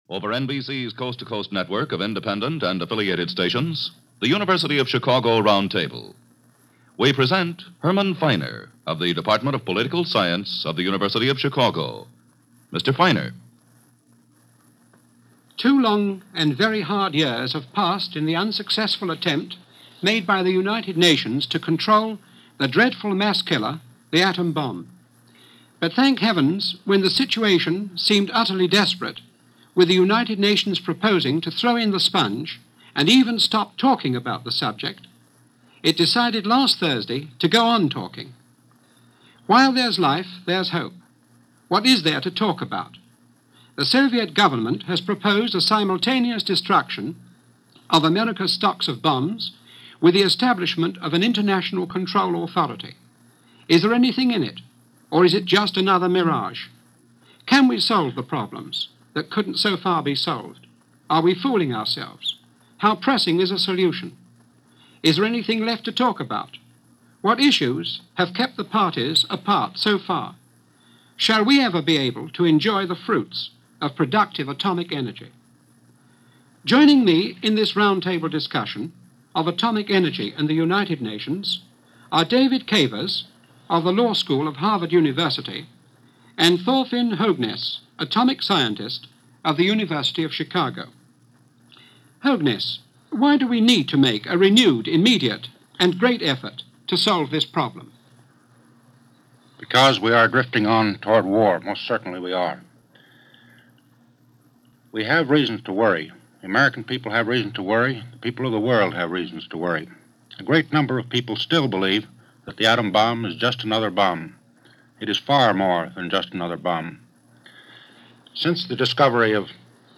Cold War - The Atomic Bomb, Atomic Energy and The United Nations - 1948 - Discussion over the peaceful use and regulation of Atomic Energy.